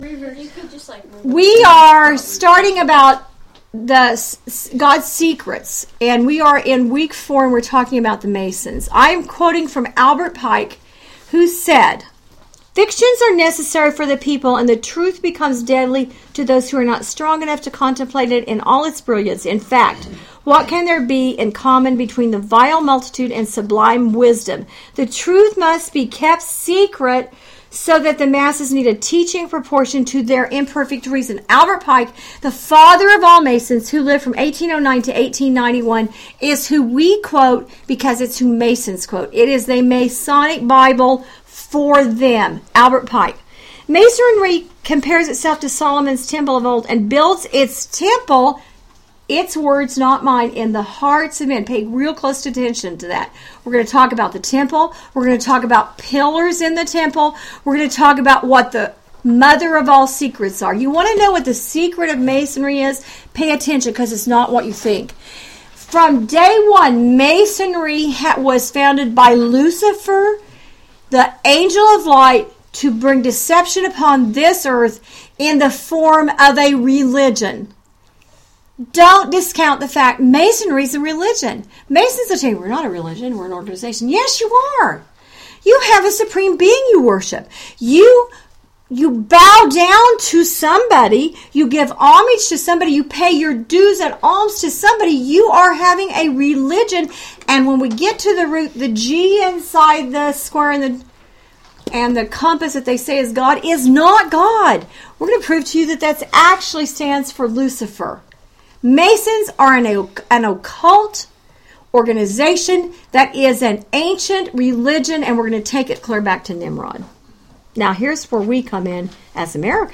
Bible Study Audio